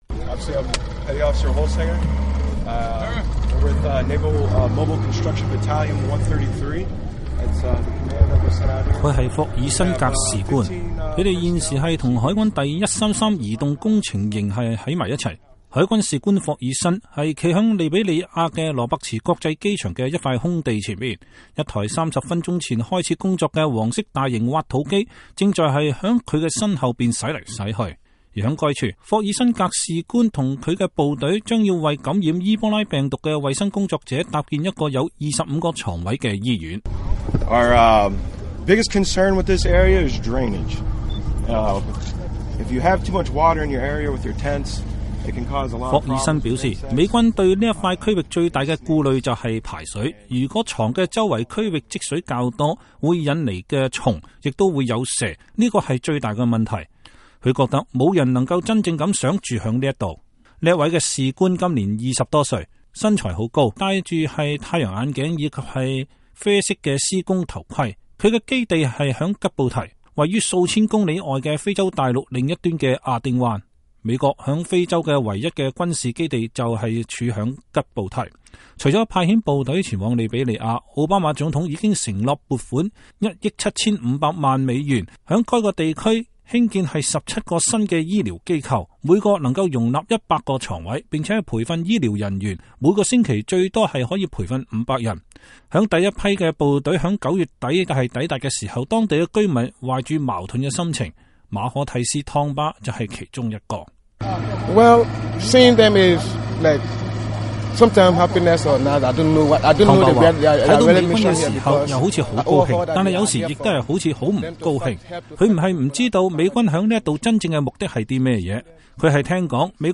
隨軍報導：美軍抵達利比里亞抗擊伊波拉